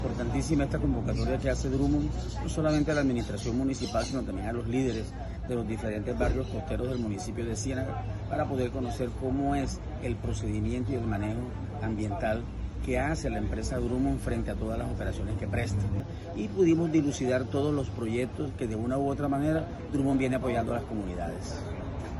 Testimonio_Alcalde-de-Cienaga-Magdalena-Luis-Fernandez-Quinto.mp3